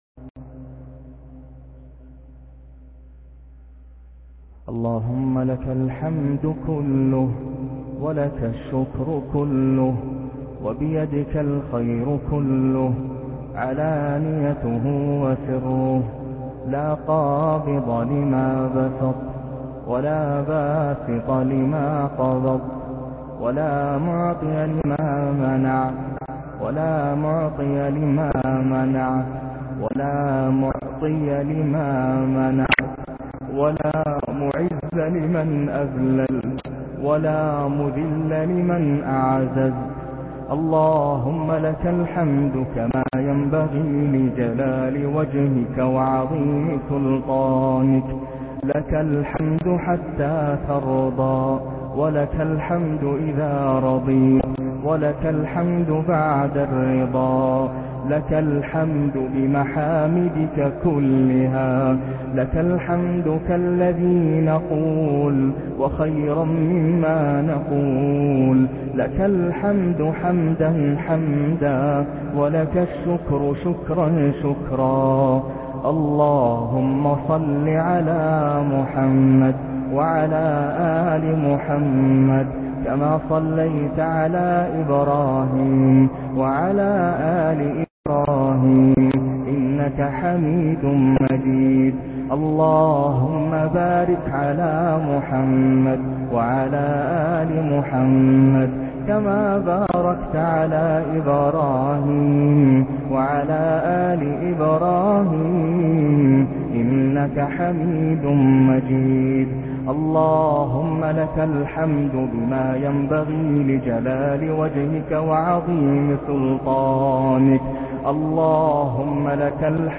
أروع دعاء للشيخ ناصر القطامى الى حد الأن مناجات رائعة جدا ليلة رمضان لا تفوت